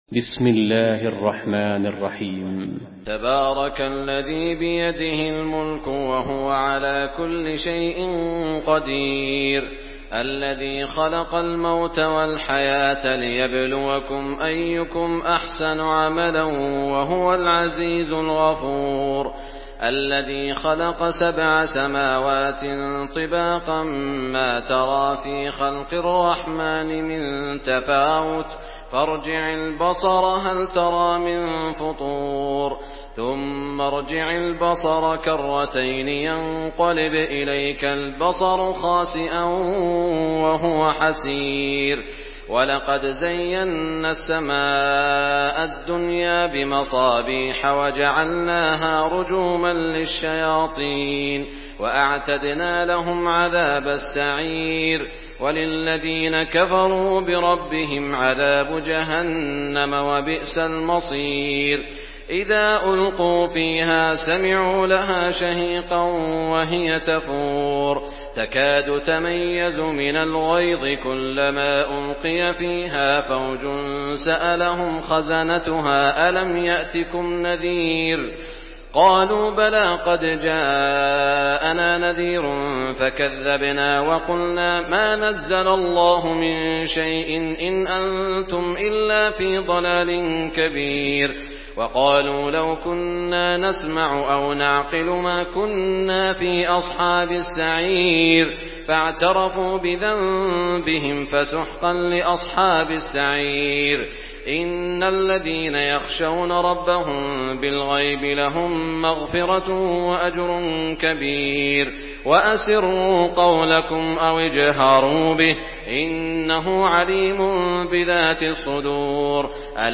Surah Al-Mulk MP3 in the Voice of Saud Al Shuraim in Hafs Narration
Murattal Hafs An Asim